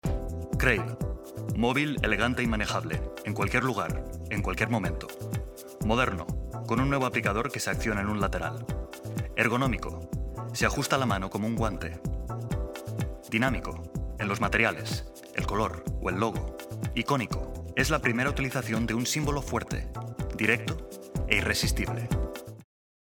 kastilisch
Sprechprobe: eLearning (Muttersprache):
Native Castilian Spanish actor with over 10 years of professional voiceover credits in TV and radio ads, audioguides, corporate films, videogames, eLearning and language courses.